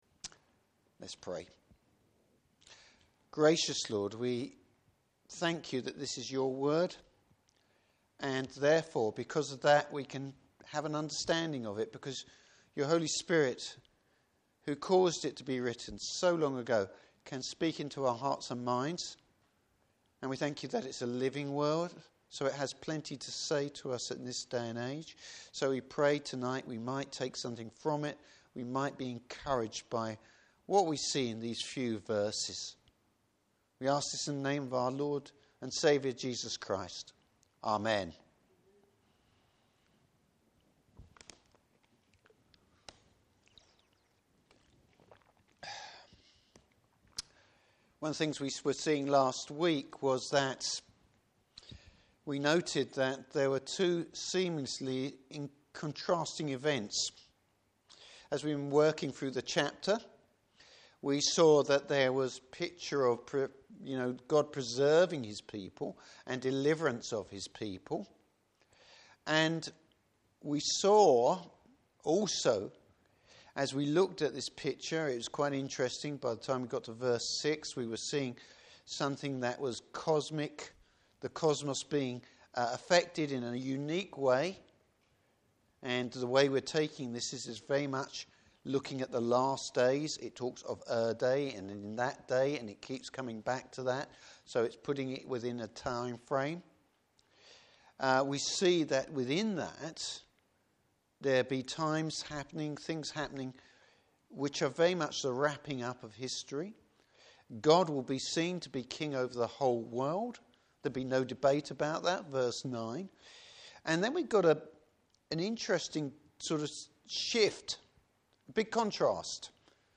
Service Type: Evening Service Surprising worshippers!